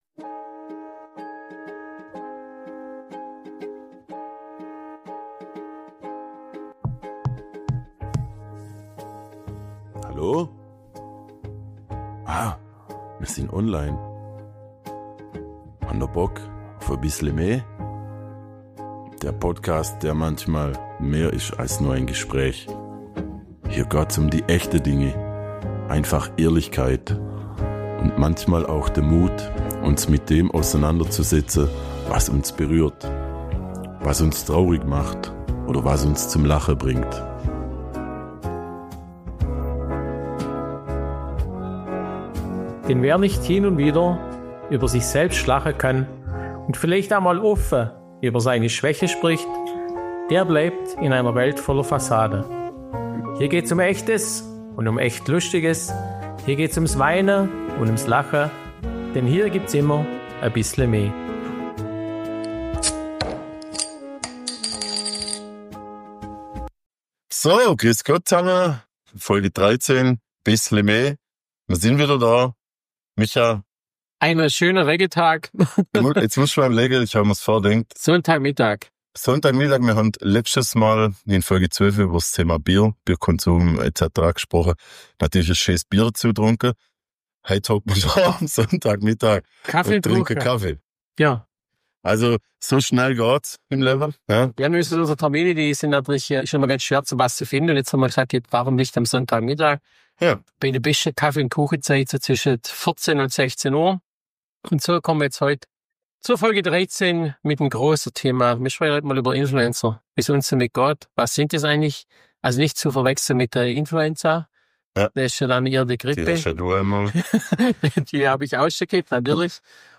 Schwoba-Podcast aus´m Schlofsack Podcast